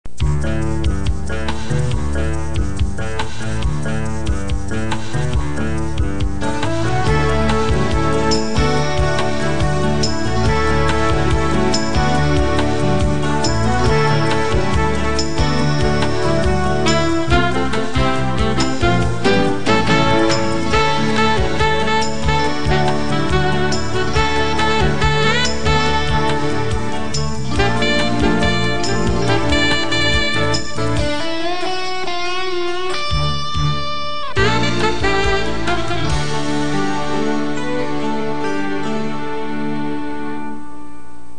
Ending Credits Score